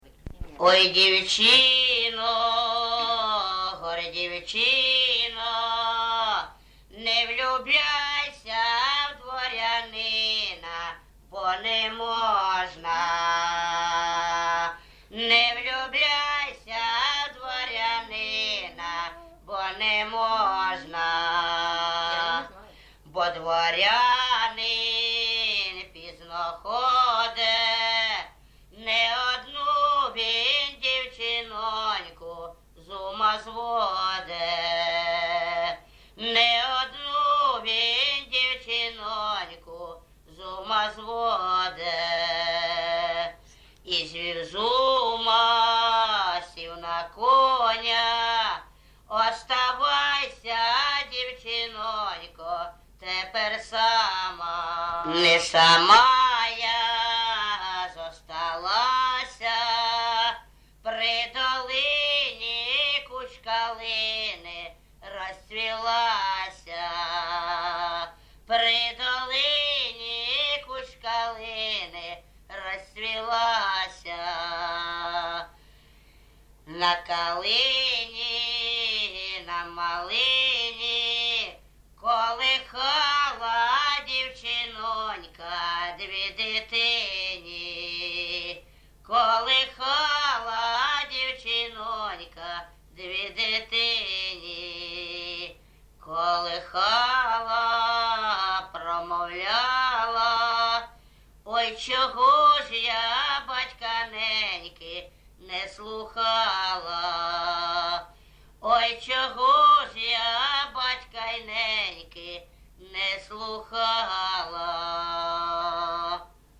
ЖанрПісні з особистого та родинного життя
Місце записус. Гарбузівка, Сумський район, Сумська обл., Україна, Слобожанщина